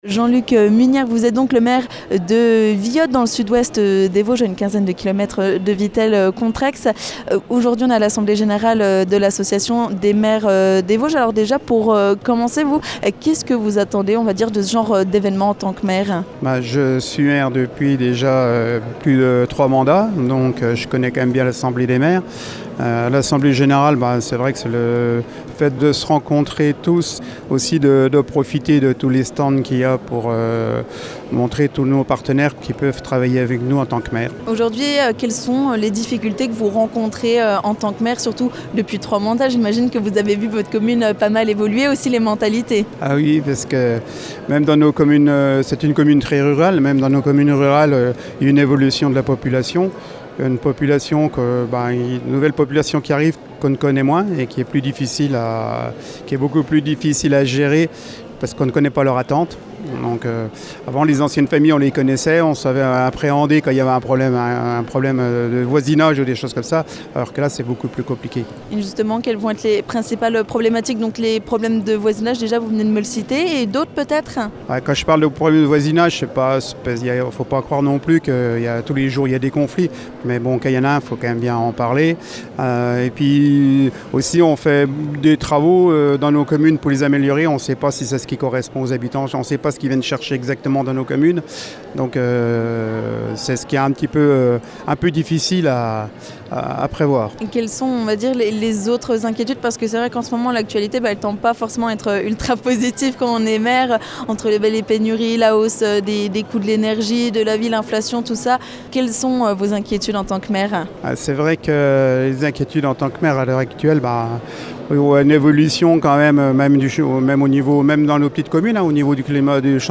Nous avons pu discuter avec Jean-Luc Munière, maire de Villotte.
Ecoutez Jean-Luc Munière, Maire de Villotte, sur Vosges FM !